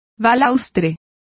Complete with pronunciation of the translation of banisters.